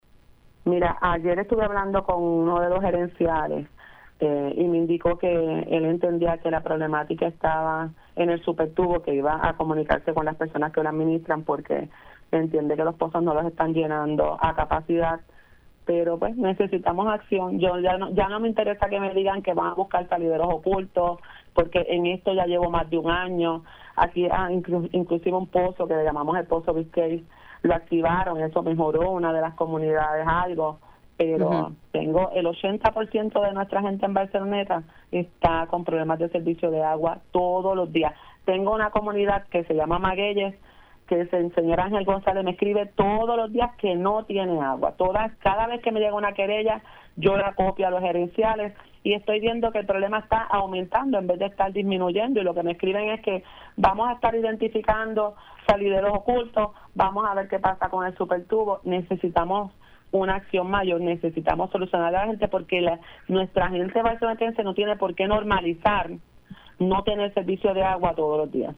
La alcaldesa de Barceloneta, Wanda Soler indicó en Pega’os en la Mañana que tuvo que declarar un estado de emergencia en su municipio debido a la emergente falta de agua potable a través de todo el pueblo.